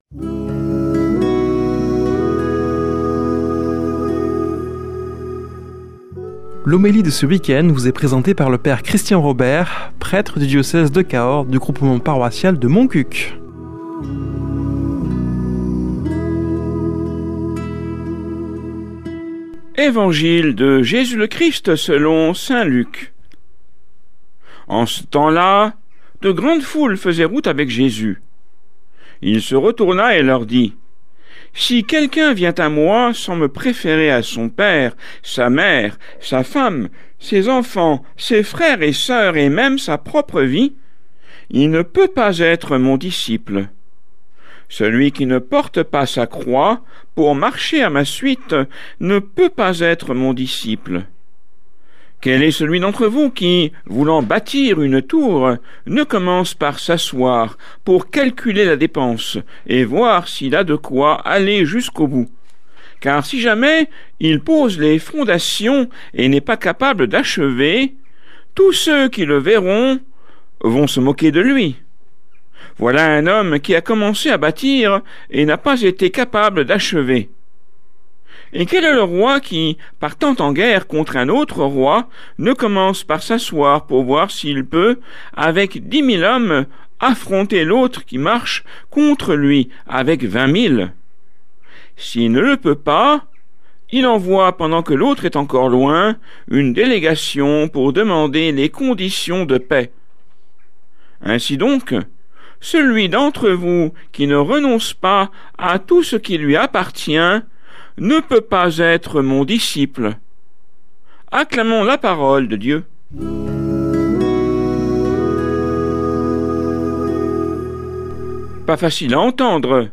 Homélie du 06 sept.